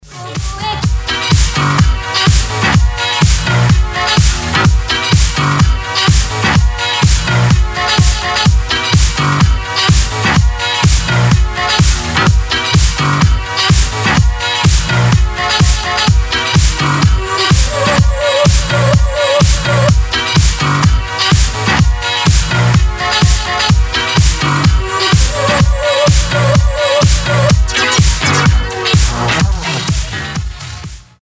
• Качество: 320, Stereo
Chill
на расслабоне
Фанк